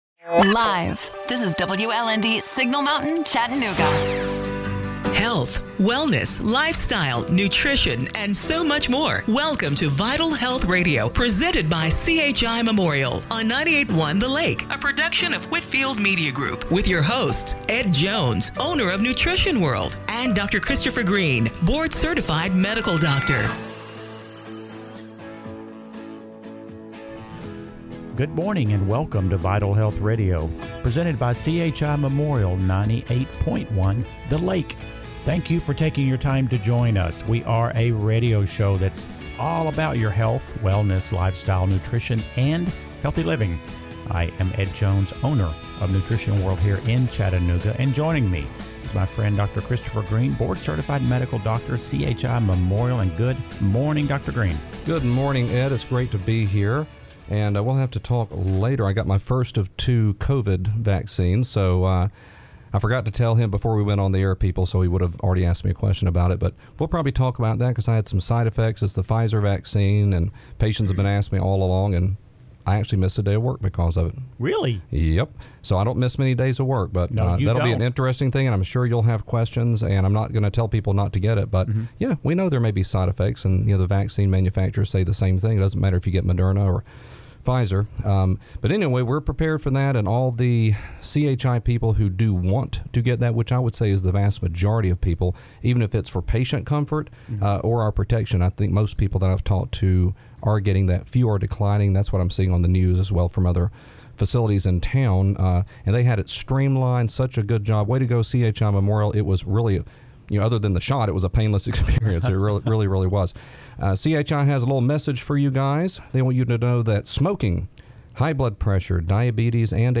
January 10 , 2021 – Radio Show - Vital Health Radio